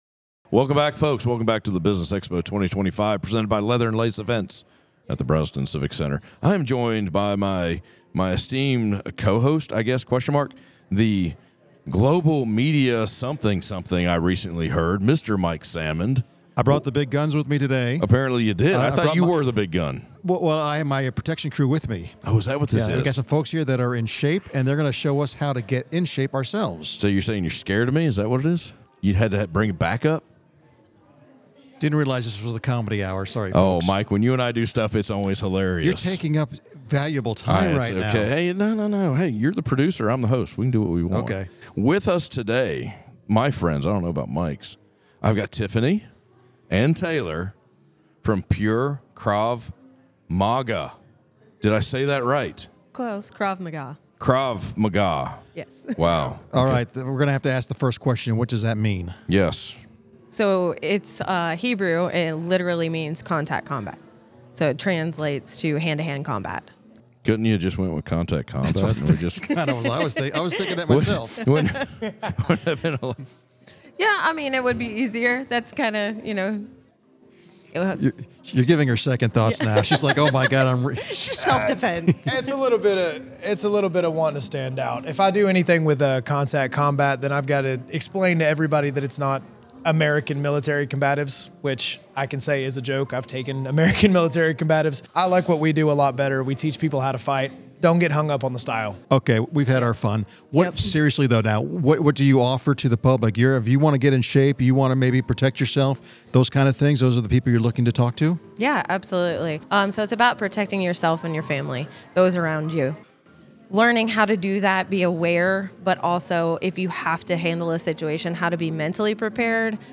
Northeast Georgia Business RadioX – the official Podcast Studio of the Business Expo 2025